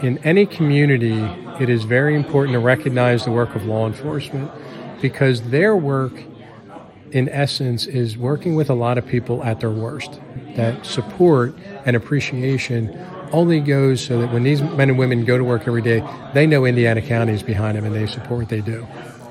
Indiana’s law enforcements officials were recognized Friday afternoon at the George E. Hood Municipal building.
District Attorney Robert Manzi hosted the event, and talked about why it’s so important to show support for local police.